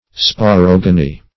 Search Result for " sporogony" : The Collaborative International Dictionary of English v.0.48: Sporogony \Spo*rog"o*ny\ (sp[-o]*r[o^]g"[-o]*n[y^]), n. [Spore + root of Gr.